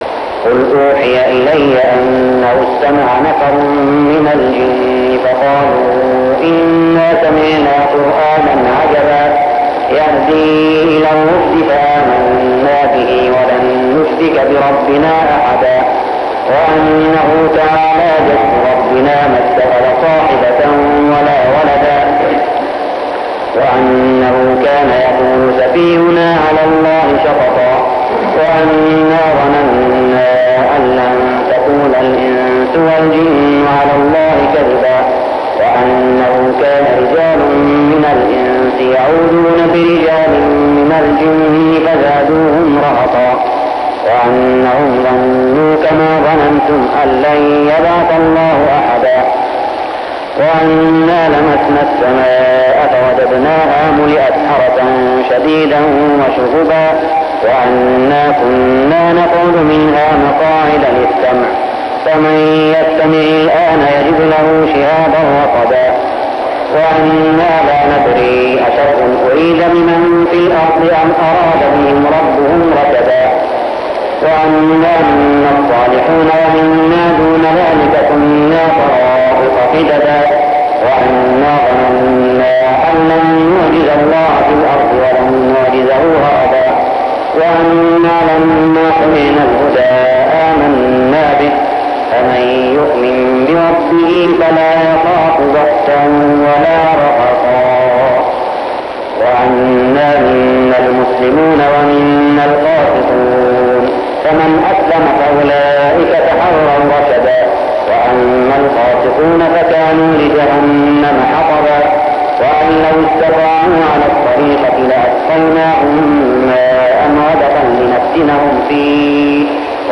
من ليالي رمضان 1405هـ من سورة الجن حتى سورة المرسلات كاملة | From nights of Ramadan from Surah Al-Jinn to Al-Mursalat > تراويح الحرم المكي عام 1405 🕋 > التراويح - تلاوات الحرمين